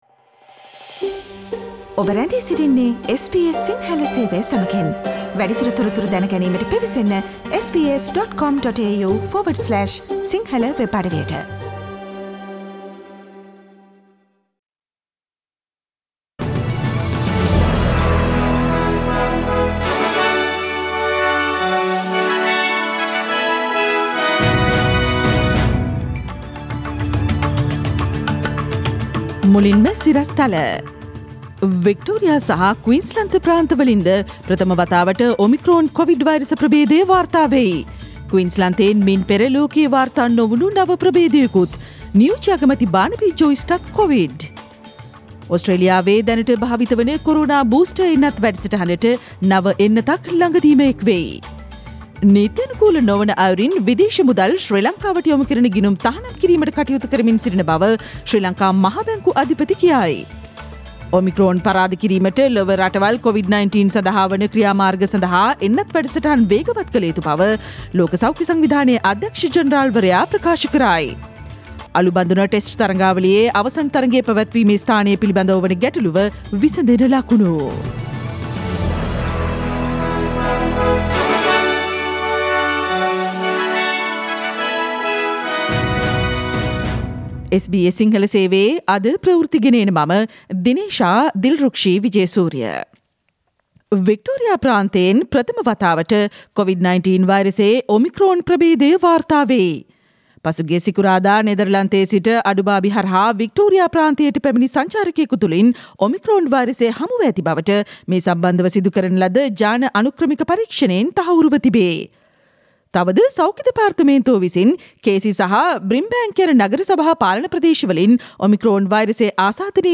2021 දෙසැම්බර් 9 වන බ්‍රහස්පතින්දා SBS සිංහල ගුවන්විදුලි වැඩසටහනේ ප්‍රවෘත්ති ප්‍රකාශයට සවන්දෙන්න ඉහත චායාරූපය මත ඇති speaker සලකුණ මත click කරන්න